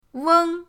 weng1.mp3